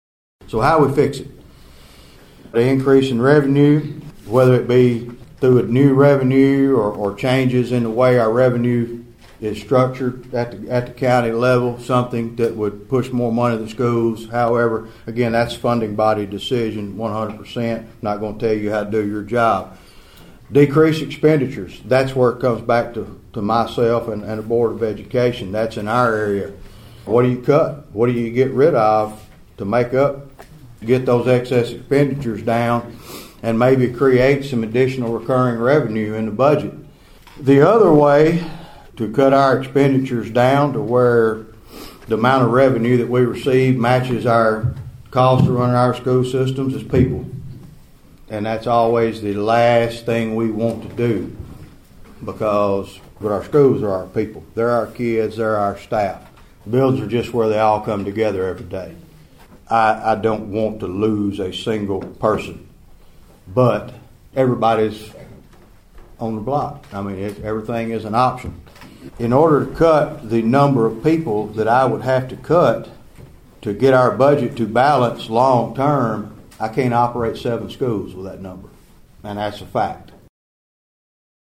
During the joint meeting at the County Courthouse, Director Watkins talked about the financial shortfall.(AUDIO)